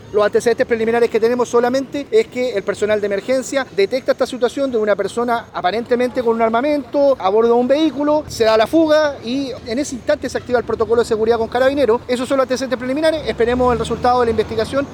El delegado presidencial del Bío Bío, Eduardo Pacheco, señaló que es un hecho que está en investigación, y que las personas en el exterior se dieron a la fuga tras la llegada de personal policial.